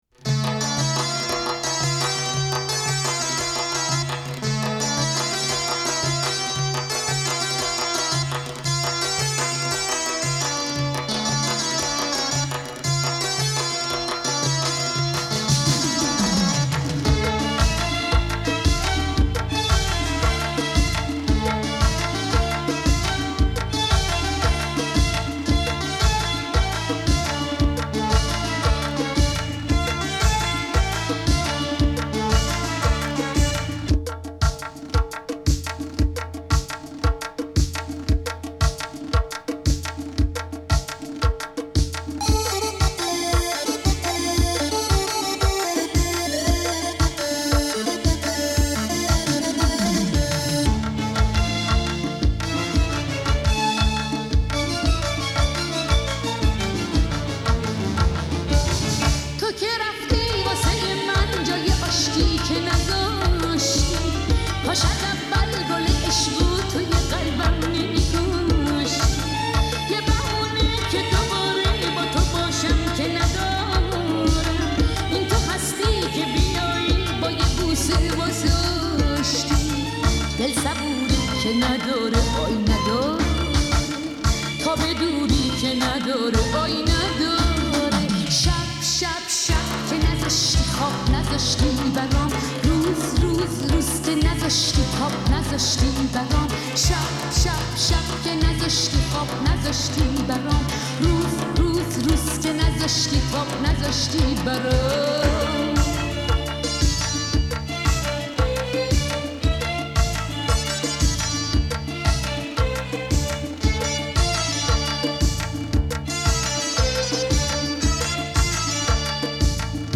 پاپ